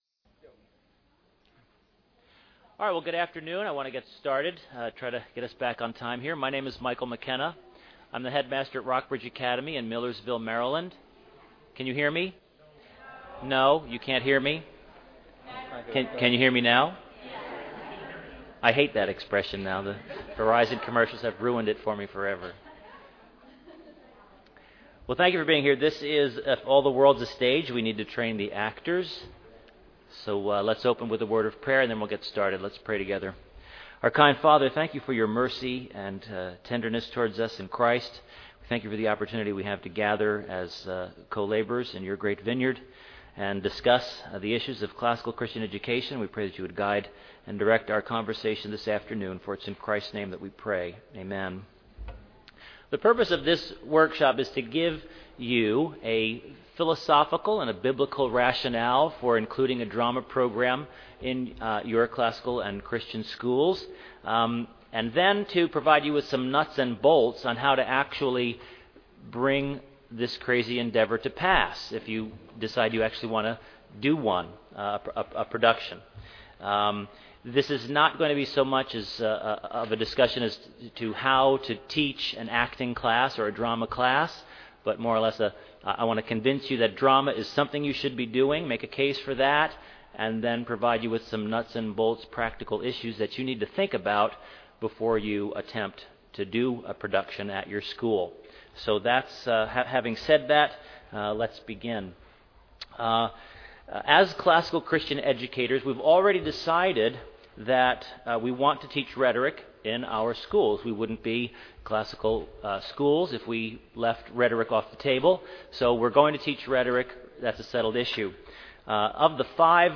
2009 Workshop Talk | 0:59:14 | All Grade Levels, Culture & Faith, Virtue, Character, Discipline